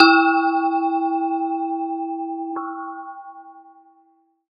PixelPerfectionCE/assets/minecraft/sounds/block/bell/use1.ogg at b8c985ad0d2216b410e9184c338cbc1eb5ae3feb